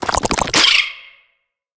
Sfx_creature_penguin_skweak_05.ogg